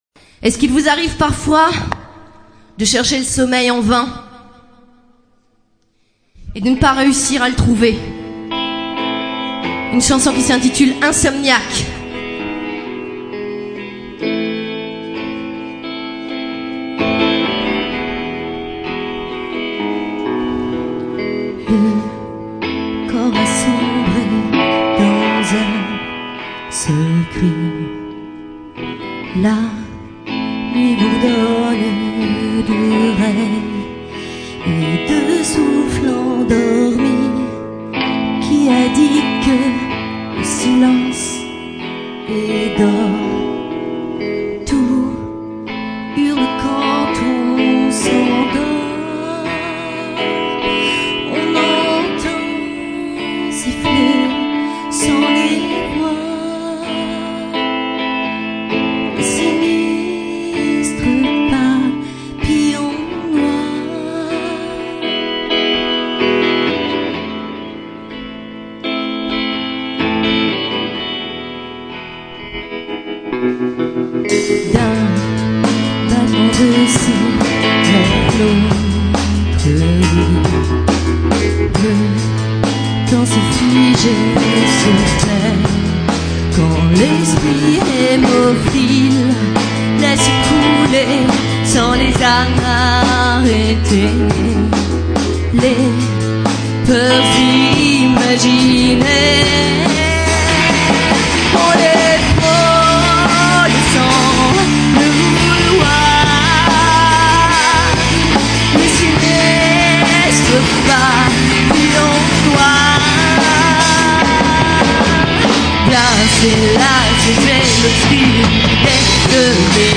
12/02/2005 - L'Espace B